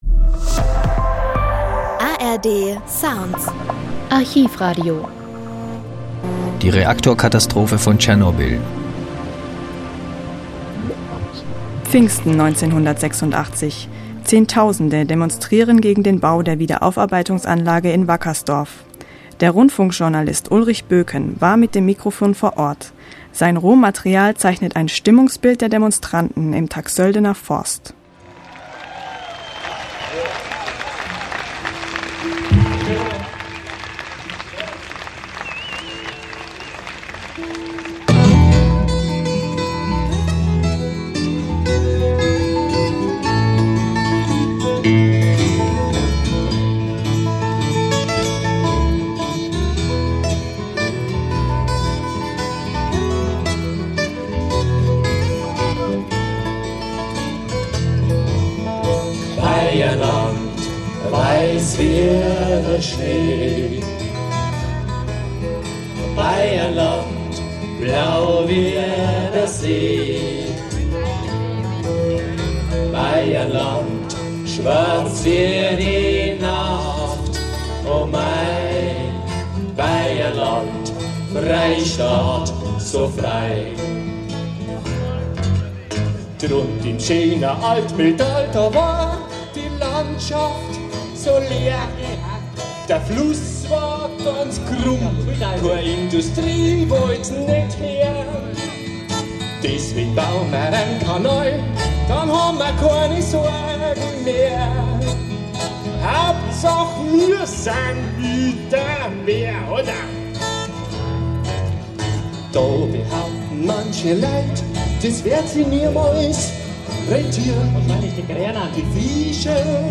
Sein Rohmaterial zeichnet ein Stimmungsbild der Demonstranten im Taxöldener Forst.
grossdemo-gegen-die-wiederaufarbeitungsanlage-wackersdorf.mp3